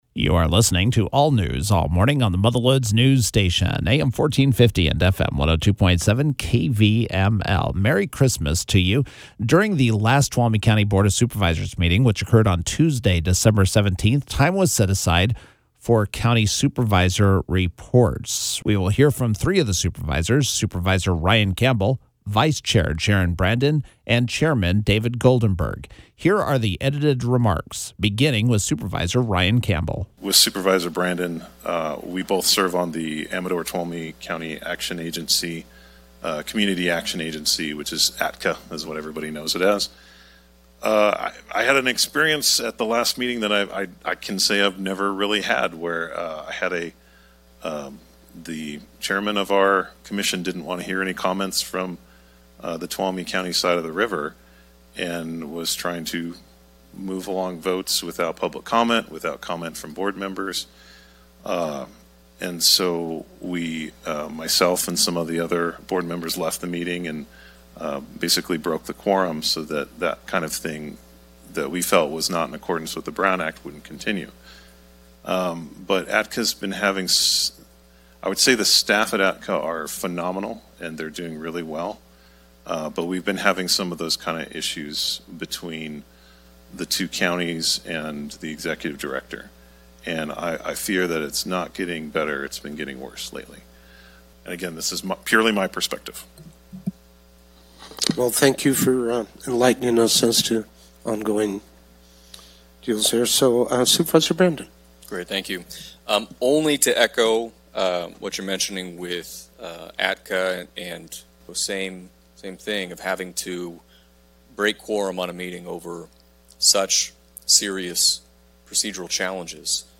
The Tuolumne County Supervisors held their final meeting of 2024 on Tuesday December 17th.
The Supervisor Reports at the end of the meeting were edited for KVML’s “Newsmaker of the Day”.